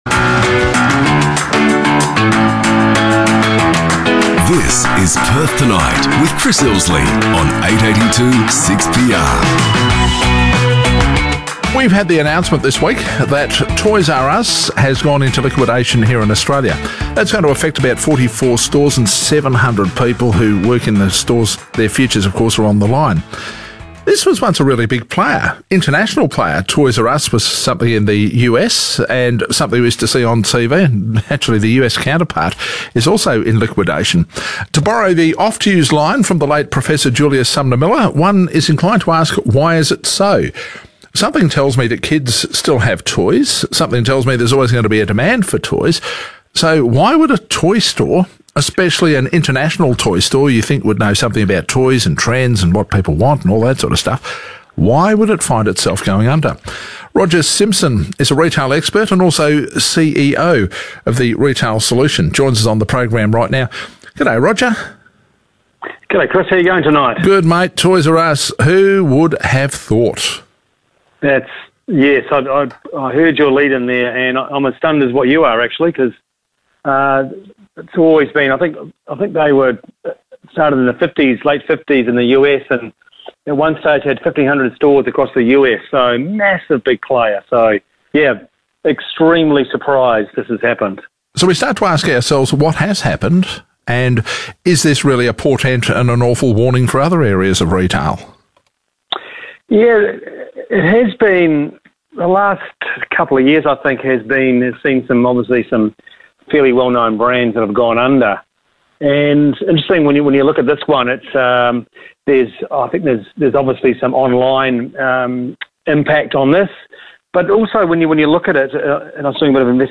Toys R Us interview.mp3